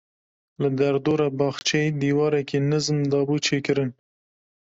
Uitgespreek as (IPA) /nɪzm/